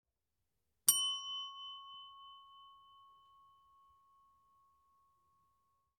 hotel bell
bell desk ding hotel OWI ring service sound effect free sound royalty free Sound Effects